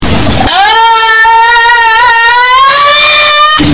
It Is Wednesday My Dudes Vine Loud